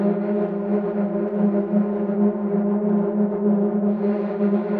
SS_CreepVoxLoopA-10.wav